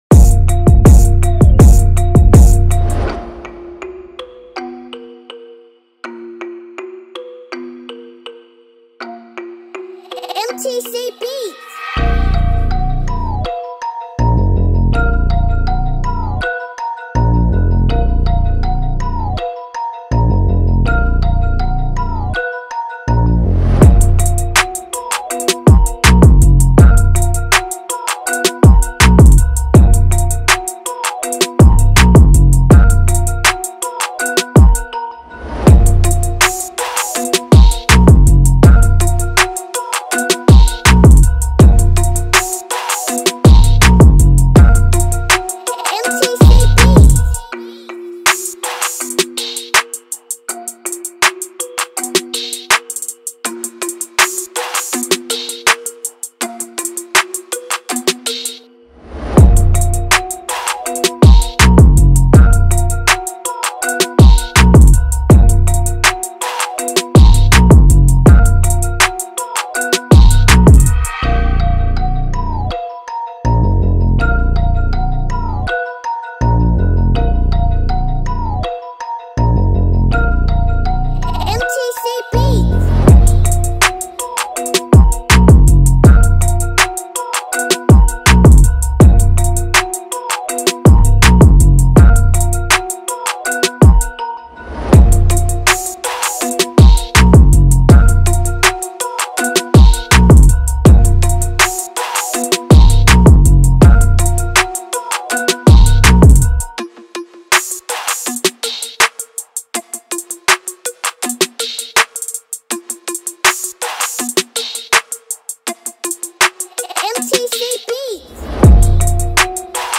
دانلود بیت ترپ اگرسیو
ژانر : ترپ مود : فری استایل | مامبل | دیس تمپو : 81 زمان